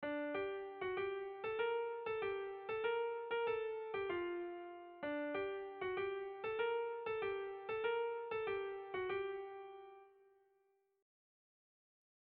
Erromantzea
Lauko txikia (hg) / Bi puntuko txikia (ip)
A1A2